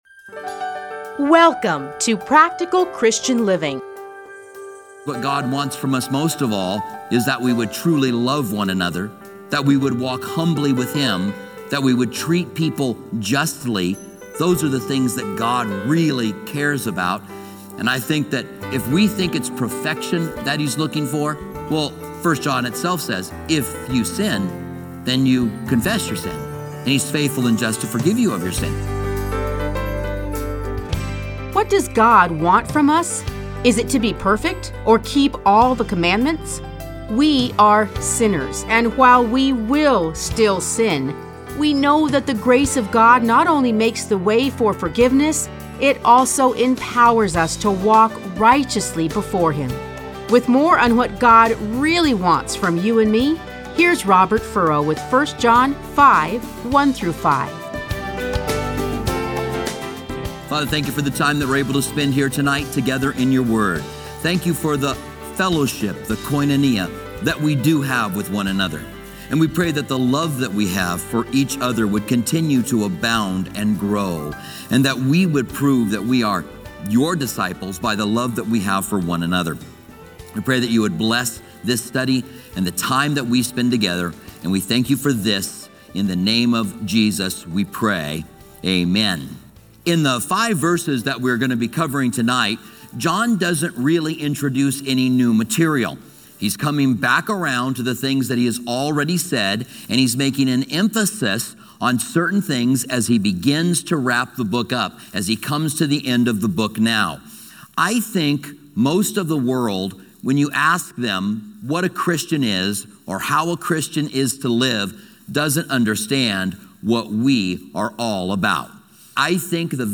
Listen to a teaching from 1 John 5:1-5.